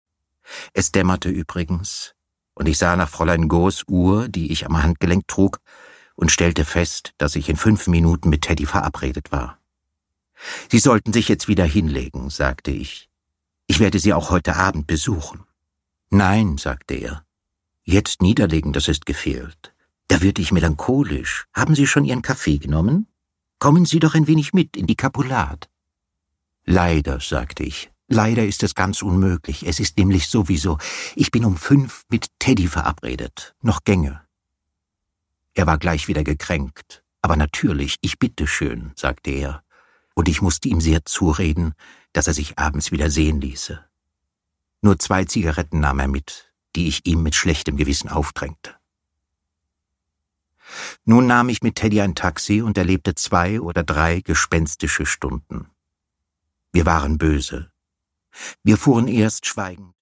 Produkttyp: Hörbuch-Download
Gelesen von: Sebastian Blomberg